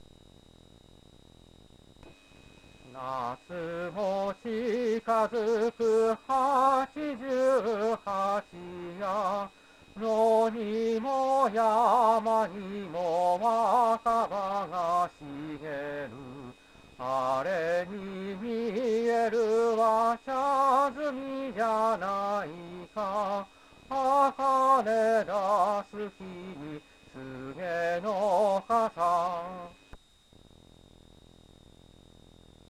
歌唱　mp3